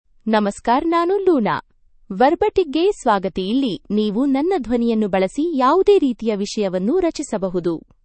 Luna — Female Kannada (India) AI Voice | TTS, Voice Cloning & Video | Verbatik AI
LunaFemale Kannada AI voice
Luna is a female AI voice for Kannada (India).
Voice sample
Listen to Luna's female Kannada voice.
Luna delivers clear pronunciation with authentic India Kannada intonation, making your content sound professionally produced.